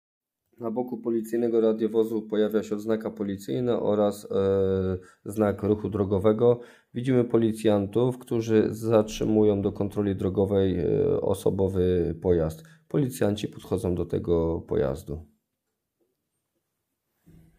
Nagranie audio audiodeskrypcja_lapki.m4a